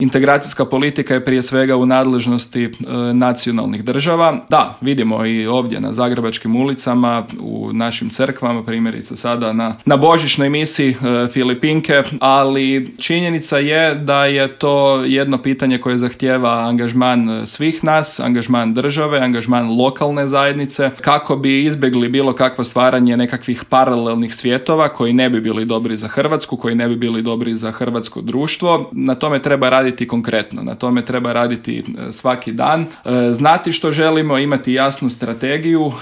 Više detalja o Paktu, kako će se odraziti na Hrvatsku, ali i o kritikama na postignuti dogovor u intervjuu Media servisa razgovarali smo s eurozastupnikom iz redova HDZ-a, odnosno EPP-a Karlom Resslerom.